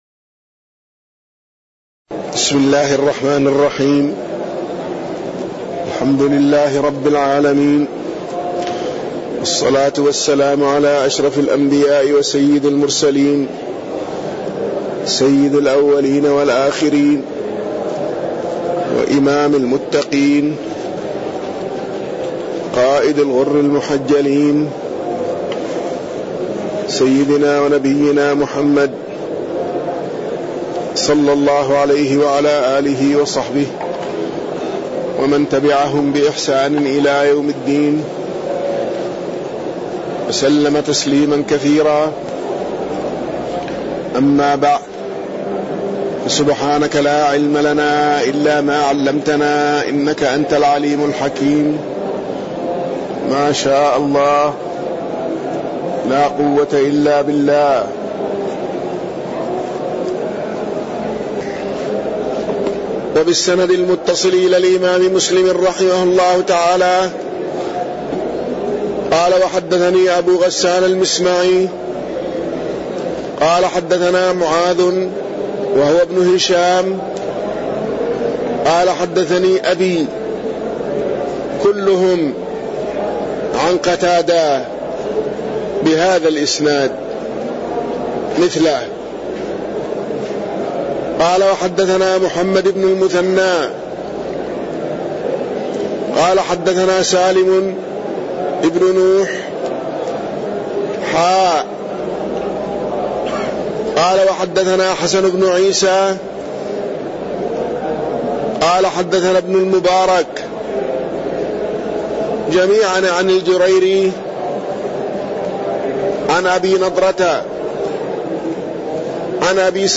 تاريخ النشر ١١ ربيع الثاني ١٤٣٠ هـ المكان: المسجد النبوي الشيخ